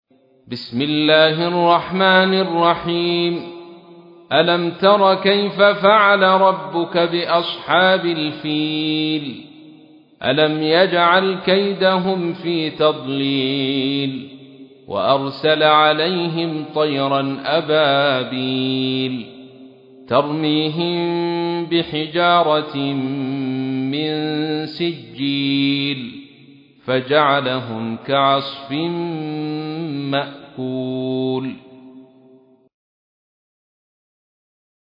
تحميل : 105. سورة الفيل / القارئ عبد الرشيد صوفي / القرآن الكريم / موقع يا حسين